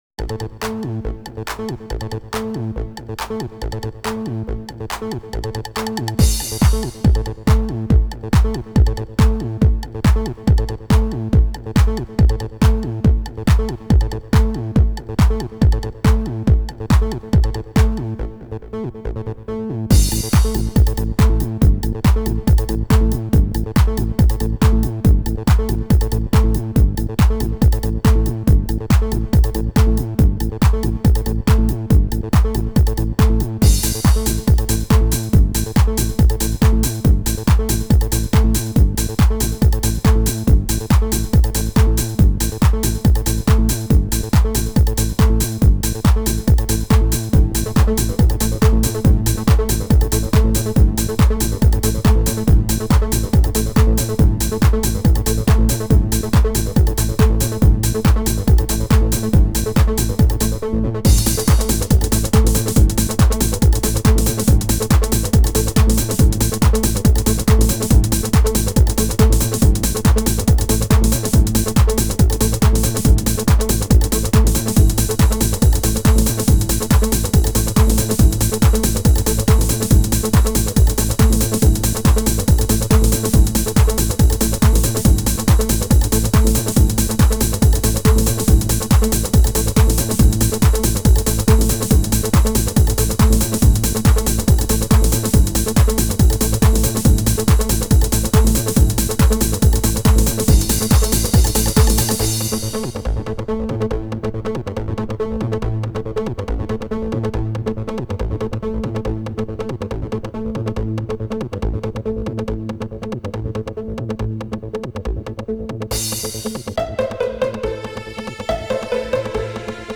Genre: Techno.